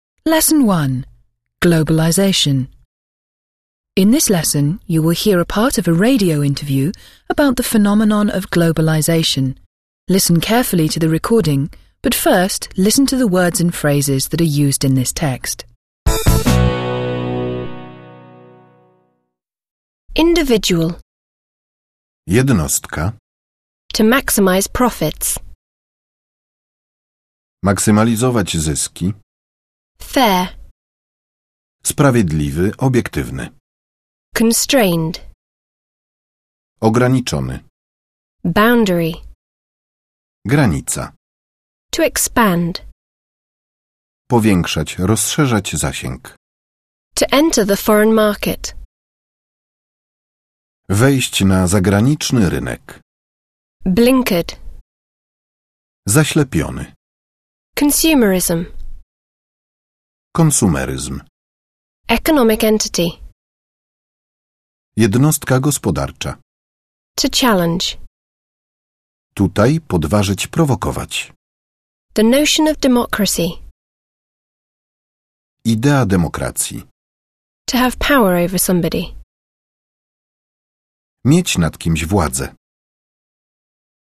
Angielski World Today News and Society - audiobook